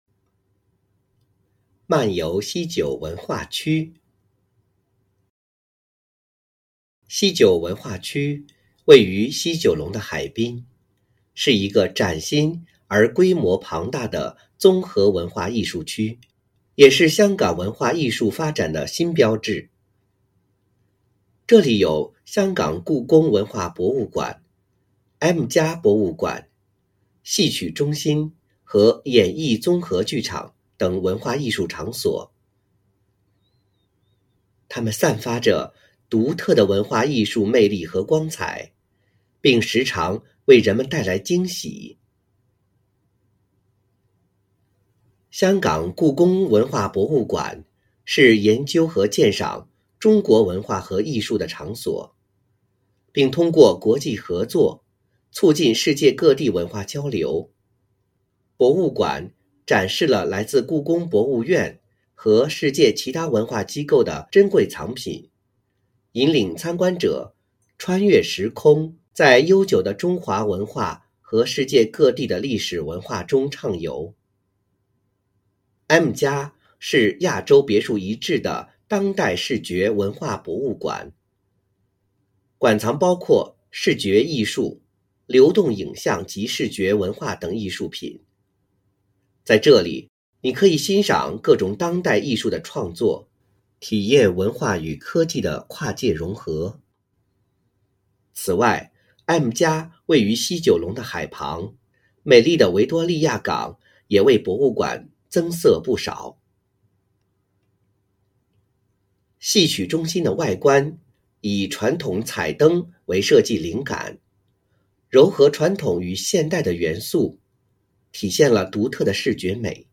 編號篇名篇章及學與教建議朗讀示範篇章附拼音